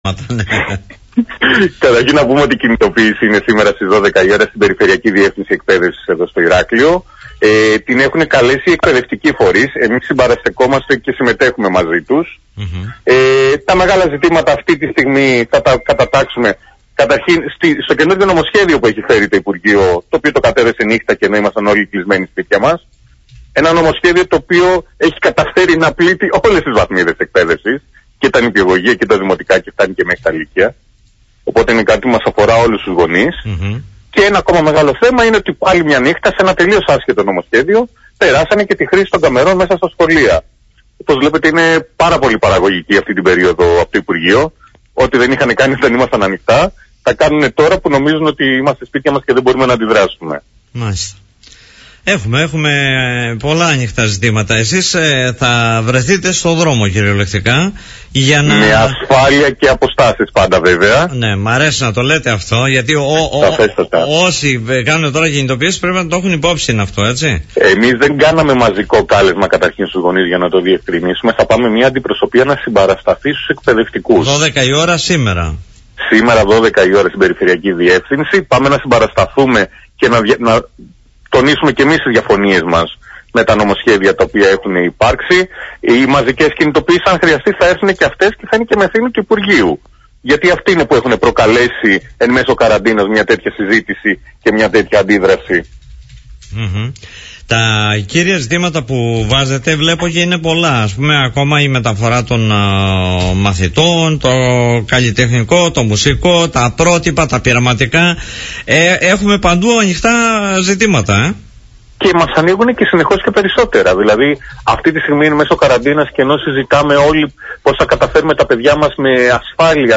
Τα θέματα που τους απασχολούν ανέπτυξε στην εκπομπή “Δημοσίως”